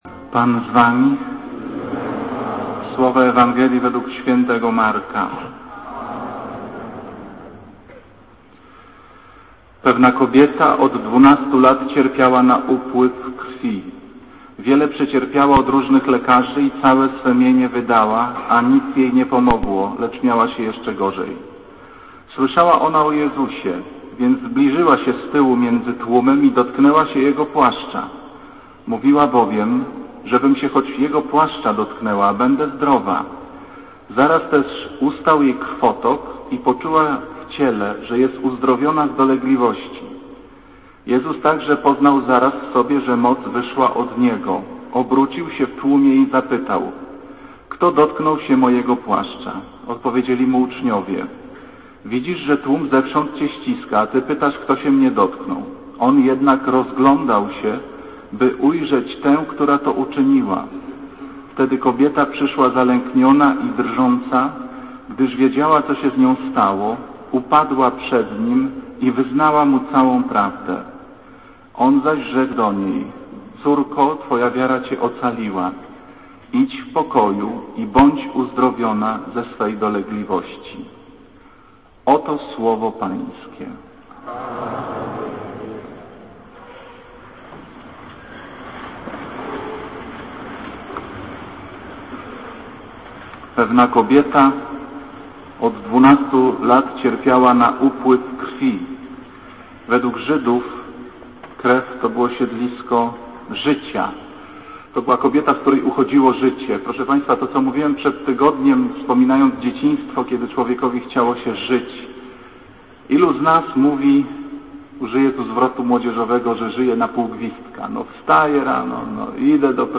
Kazanie z 2 lipca 2006r.